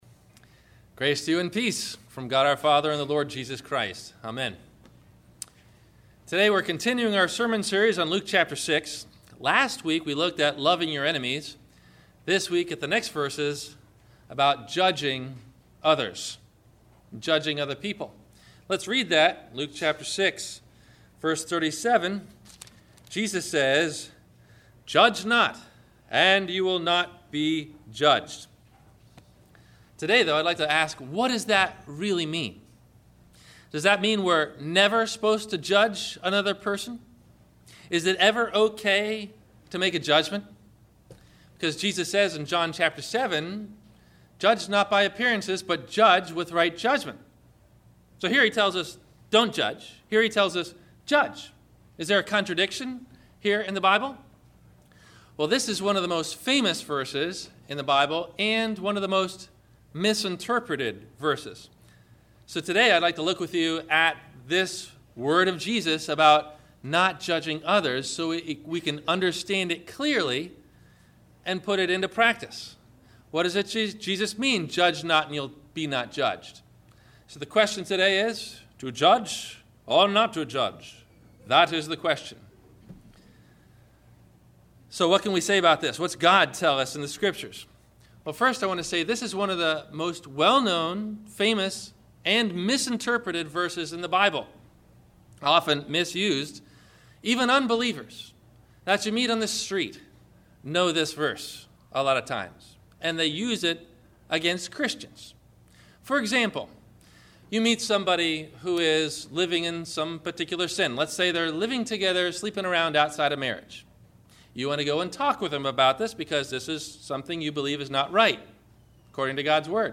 Why Are There So Many Denominations ? – (Example: ELCA and LCMS Differences ) – Sermon – August 18 2013